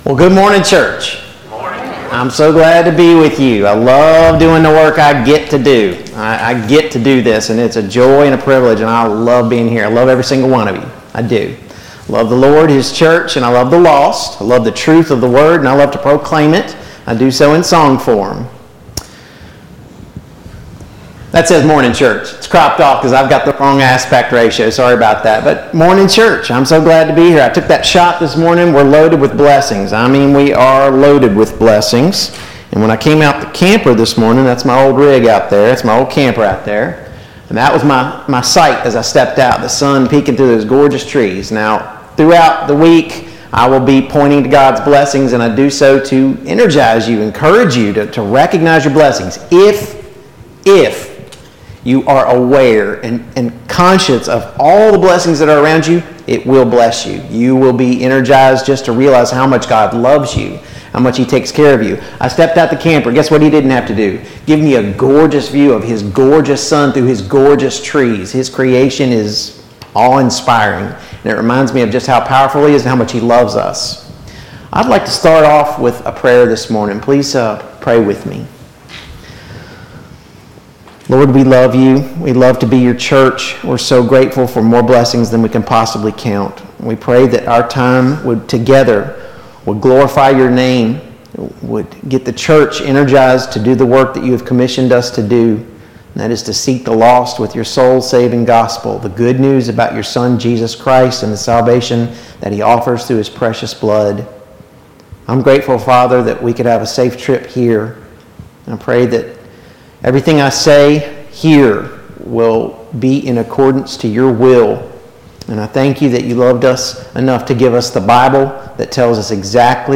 2022 Spring Gospel Meeting Service Type: Gospel Meeting Download Files Notes « 27.